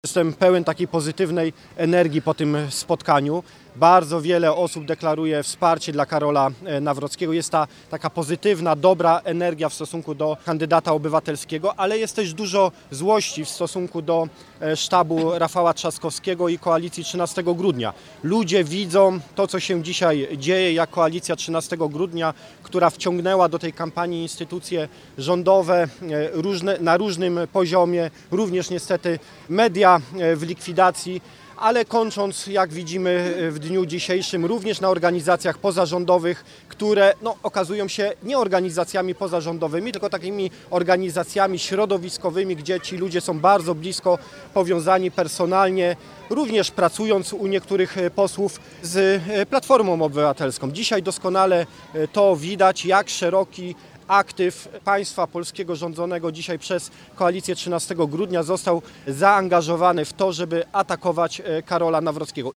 Z kolei poseł Paweł Hreniak, który wrócił ze spotkania z mieszkańcami Środy Śląskiej mówił, że wraca pełen optymizmu.